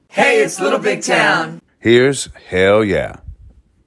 Audio / LINER Little Big Town (Hell Yeah) 3